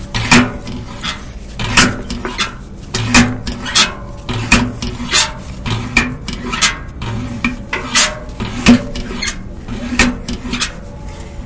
描述：金属垃圾箱。
来自Escola Basica Gualtar（葡萄牙）及其周围环境的现场录音，由8岁的学生制作。